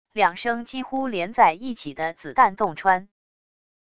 两声几乎连在一起的子弹洞穿wav音频